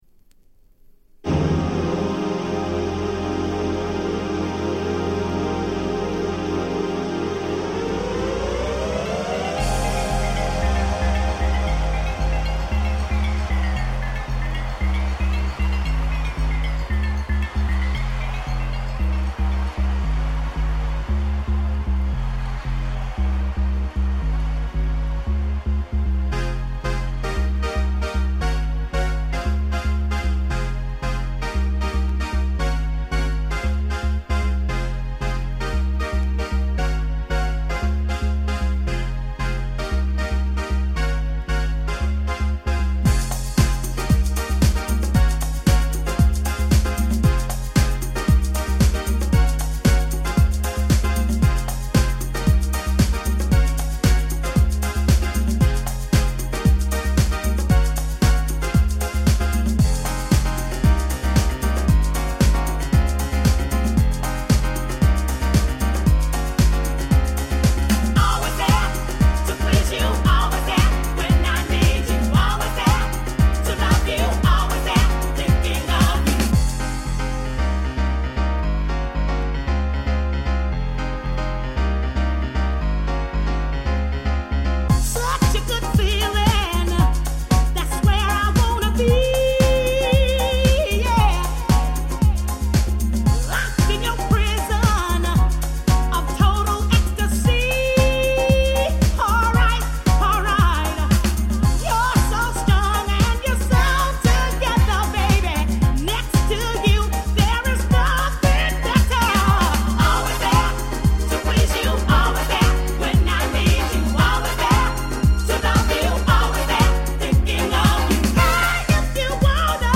91' Big Hit Acid Jazz !!